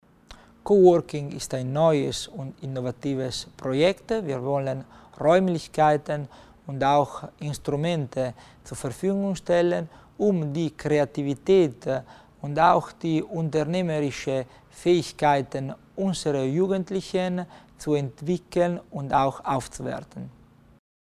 Bei seinem Mediengespräch zum Legislaturende hat sich Landesrat Christian Tommasini heute (1. August) für neue, innovative Arbeitsformen für junge Menschen stark gemacht und als konkretes Beispiel das Coworking genannt.